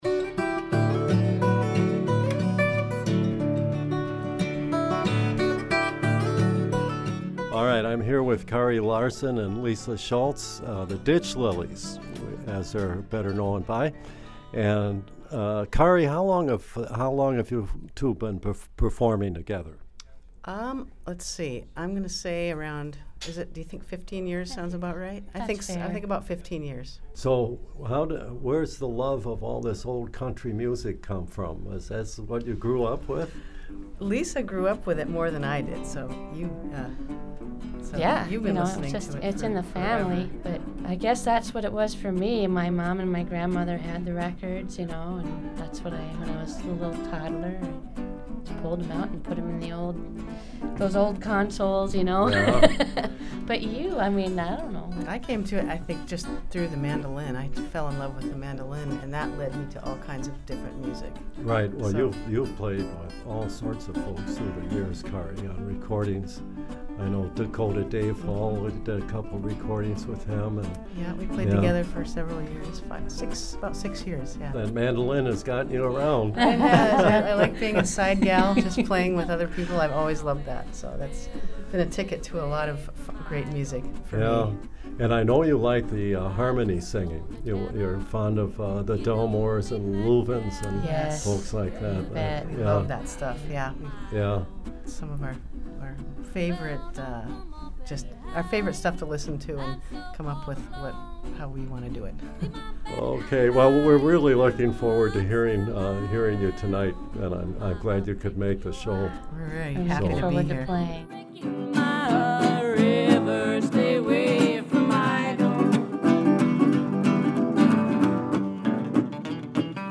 In this edition of the Local Music Project we’ll hear those interviews as well as a few segments from Saturday’s performance.